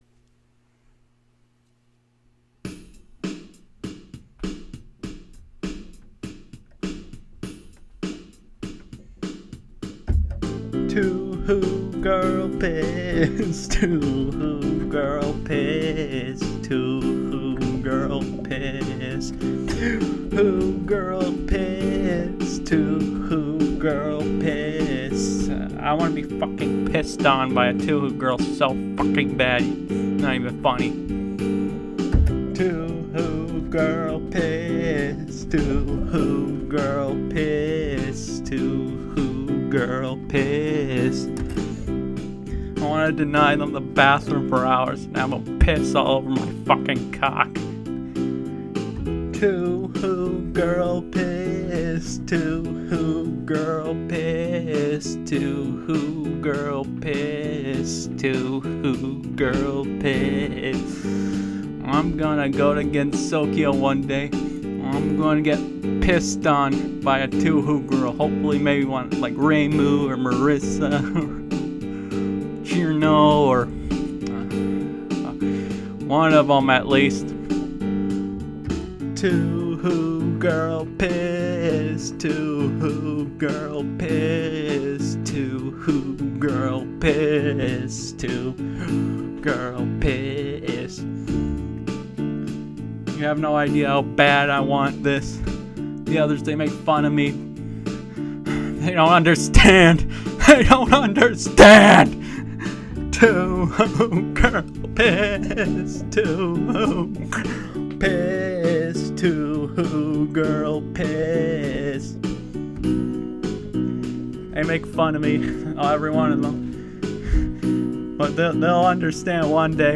Touhou Girl Piss" sung in studio(my bedroom)